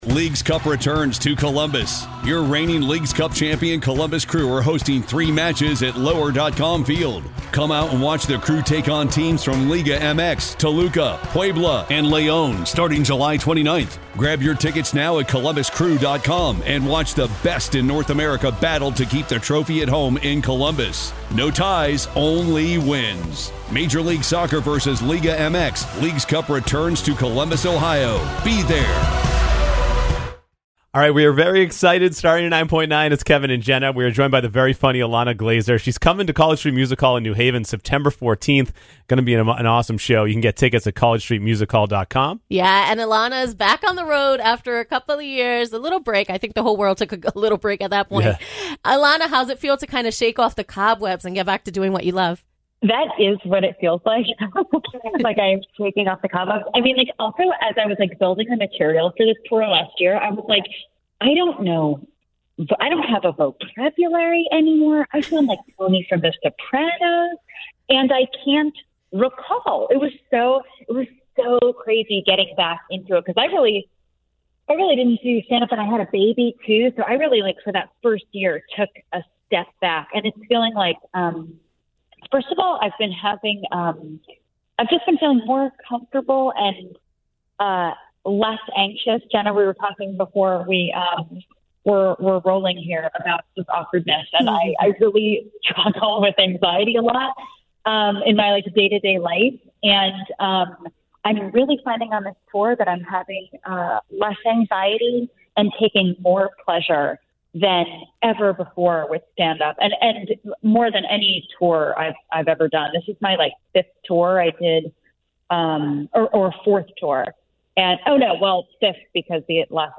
We talk to EMMY-nominated actor and comedian Ilana Glazer!